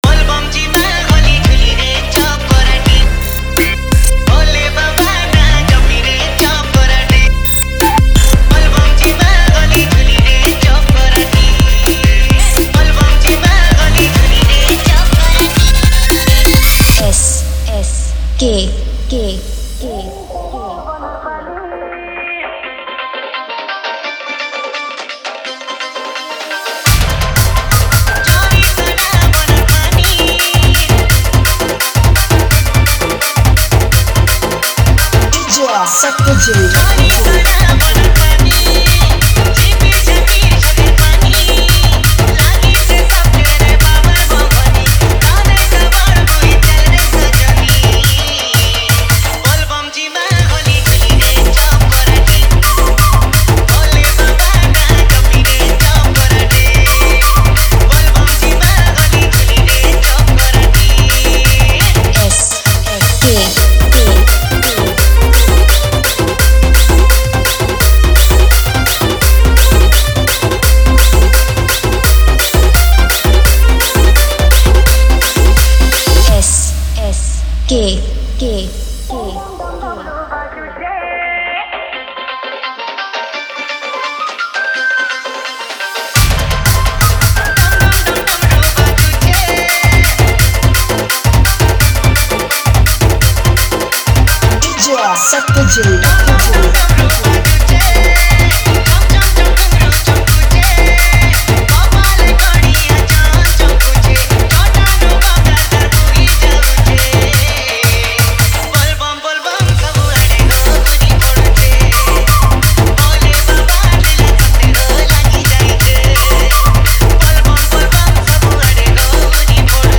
Bolbum Special Dj Song